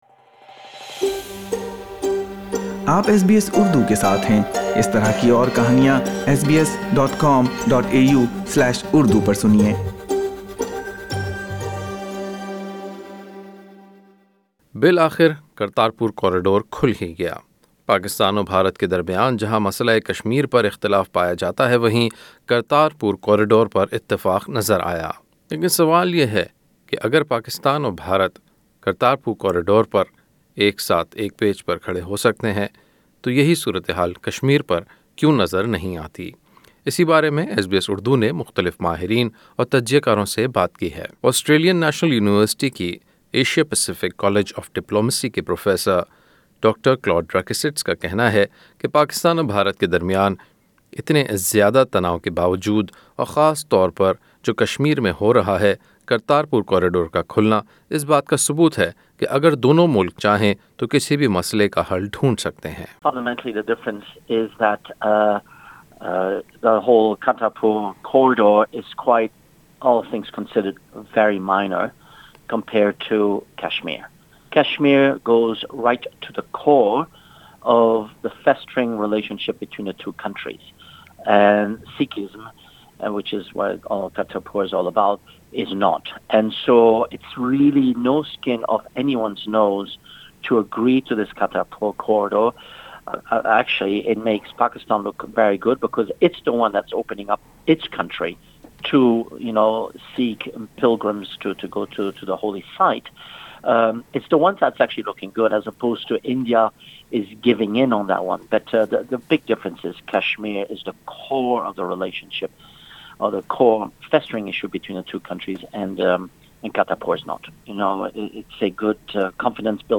سنیئے سیاسی اور بین القوامی امور کے ماہرین کا تبصرہ۔